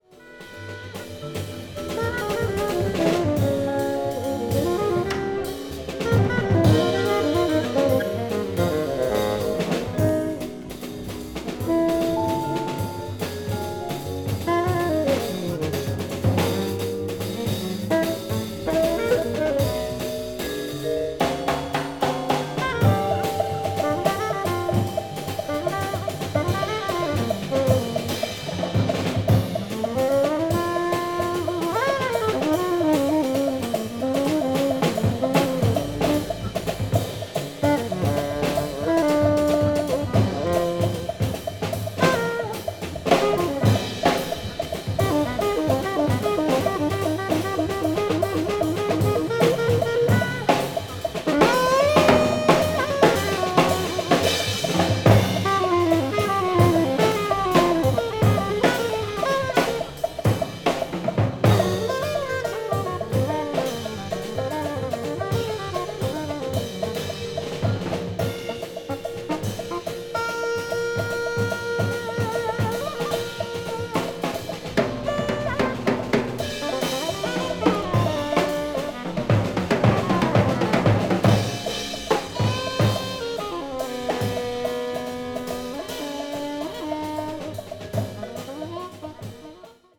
bop   bossa jazz   cool jazz   modern jazz